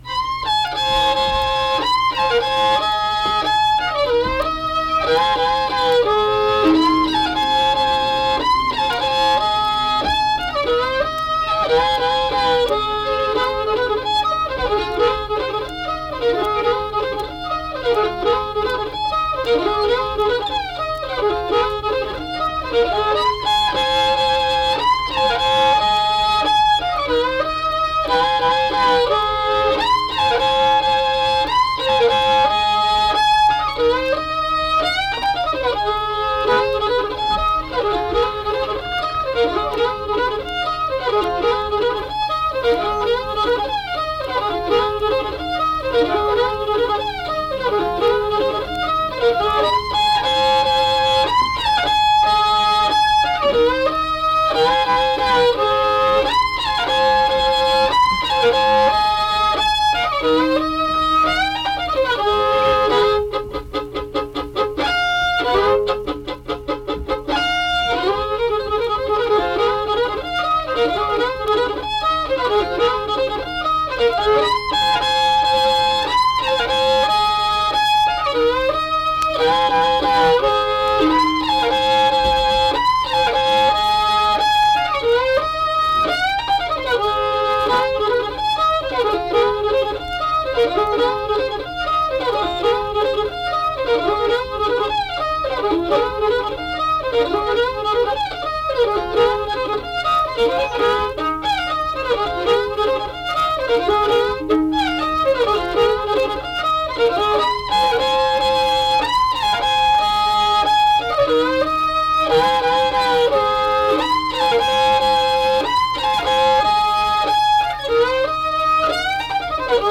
Unaccompanied fiddle music and accompanied (guitar) vocal music performance
Instrumental Music
Fiddle
Braxton County (W. Va.)